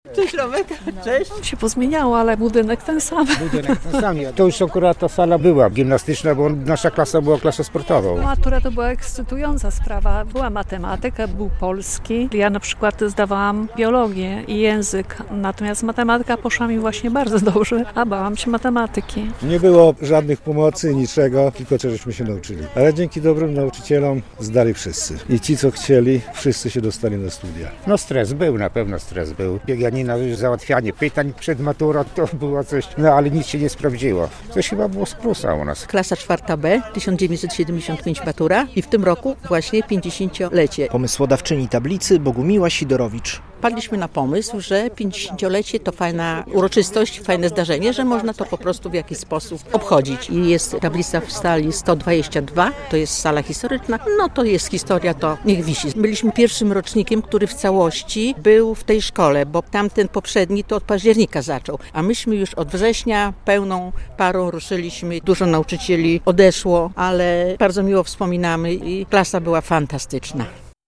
W białostockim liceum znalazła się tablica ufundowana przez absolwentów. Maturę zdawali 50 lat temu - relacja